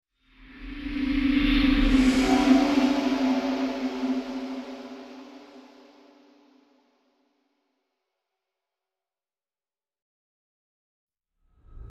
На этой странице собраны звуки, ассоциирующиеся с черной магией: загадочные шёпоты, ритуальные напевы, эхо древних заклинаний.
Звук вжух от темной магии